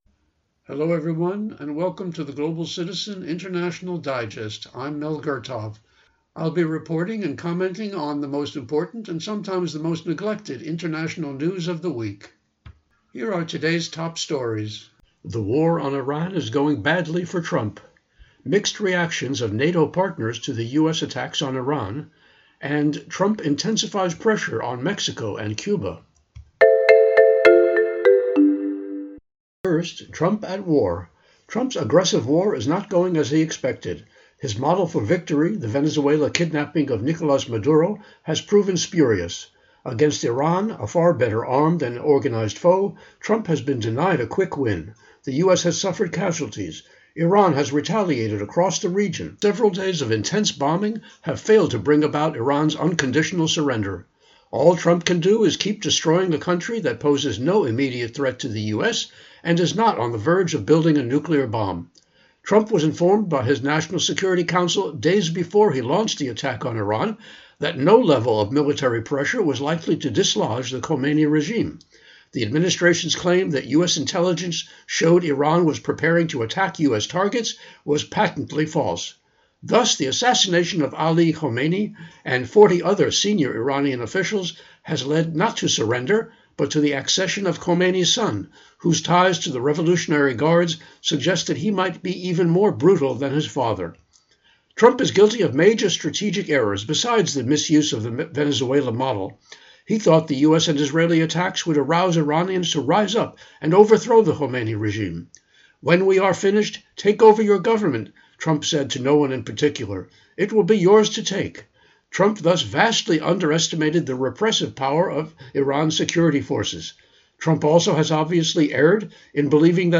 Genre(s): Public Affairs